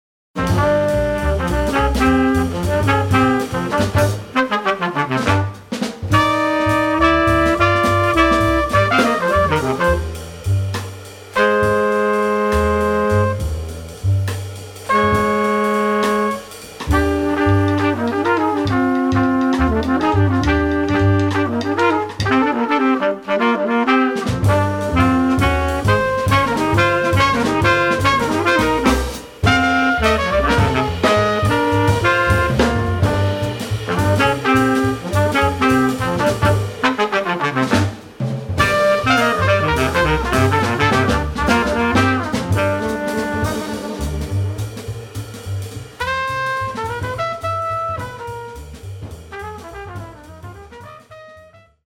trumpet
sax
bass
drums